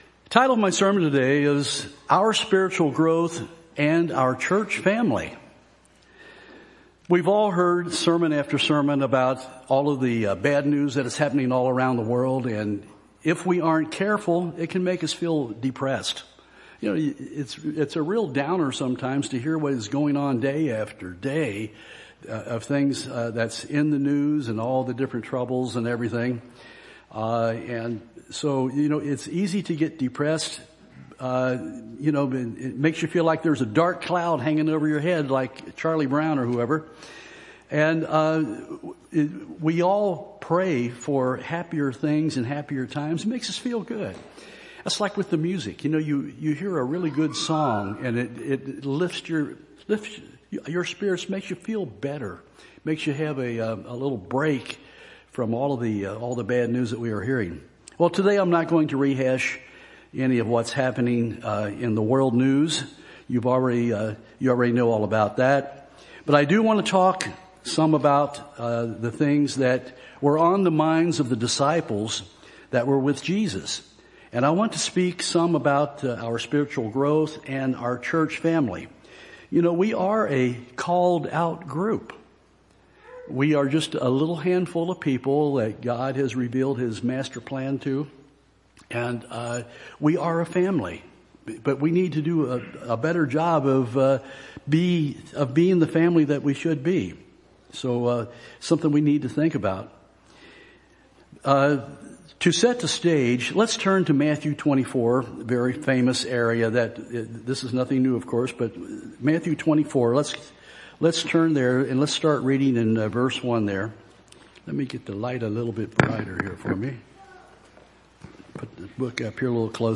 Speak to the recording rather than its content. Given in Nashville, TN